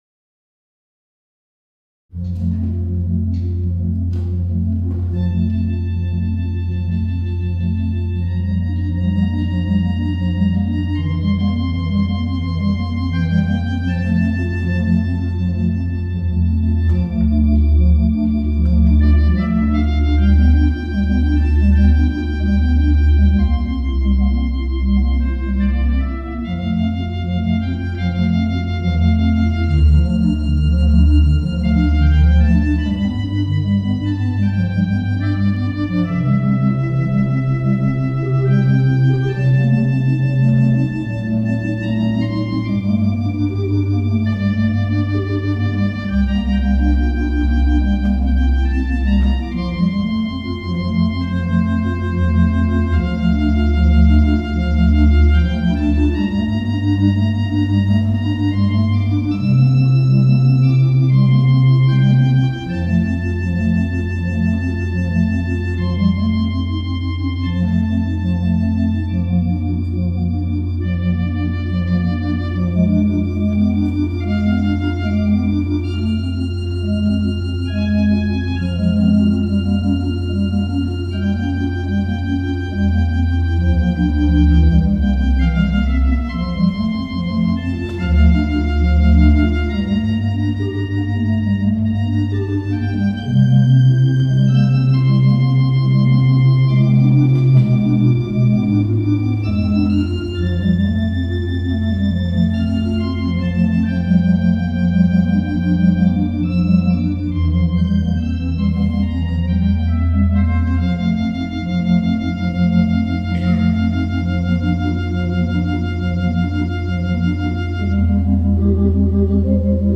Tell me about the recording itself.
and in front of an audience of over 200 attendees